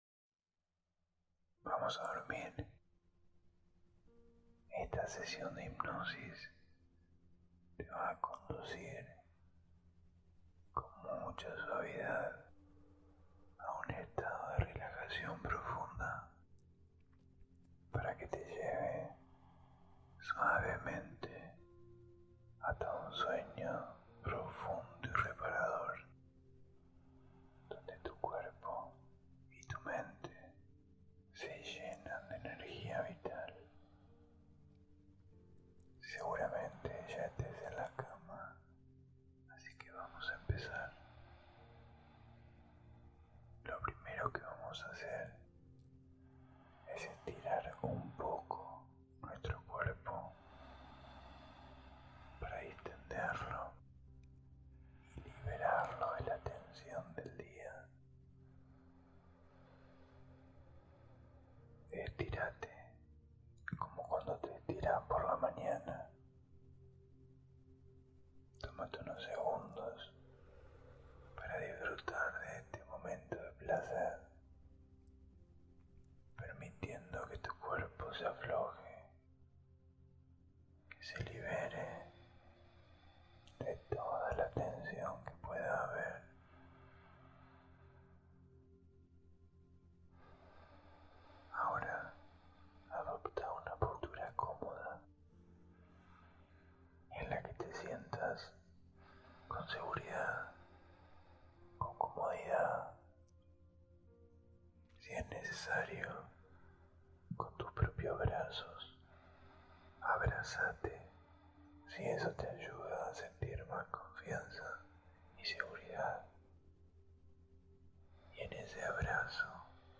Hipnosis para Dormir 7
Con susurros.